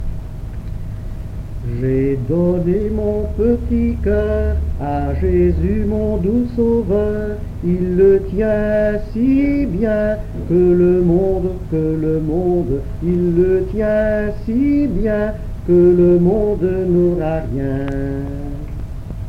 Genre : chant
Type : enfantine
Lieu d'enregistrement : Seraing
Support : bande magnétique